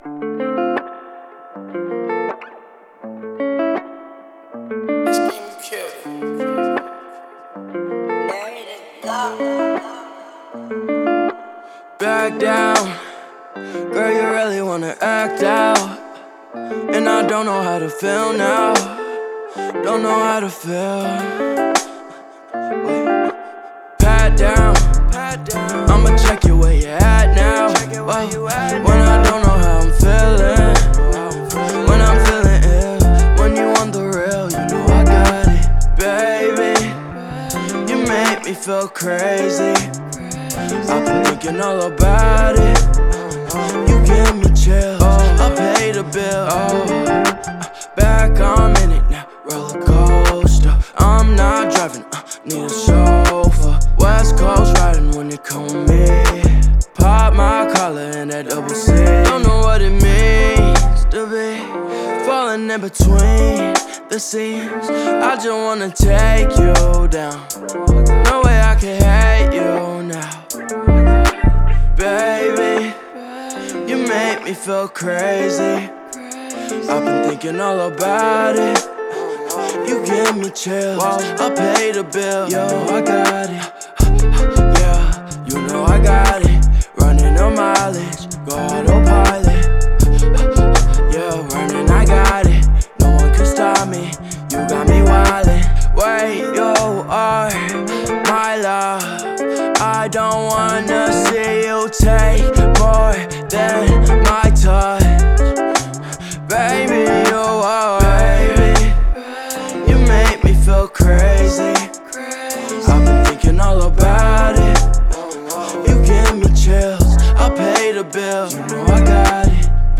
мощный бит и атмосферное звучание